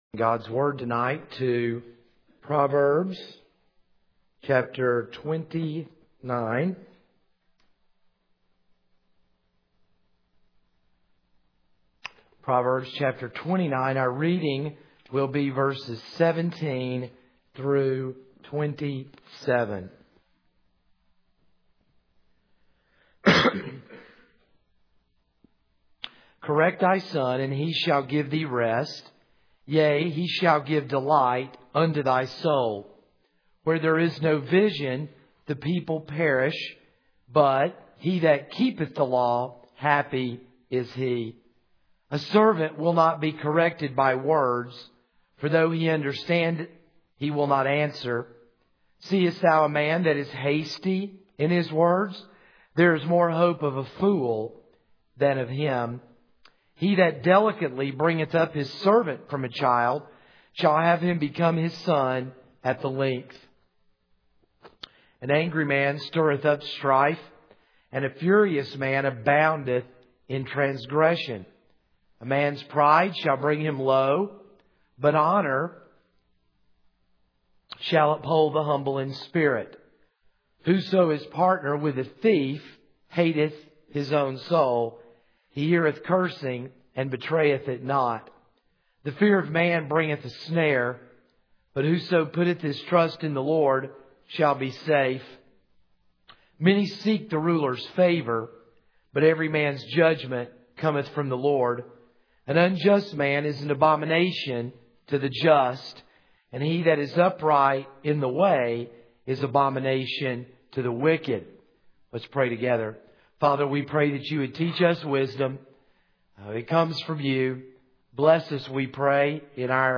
This is a sermon on Proverbs 29:17-27.